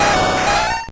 Cri de Galopa dans Pokémon Or et Argent.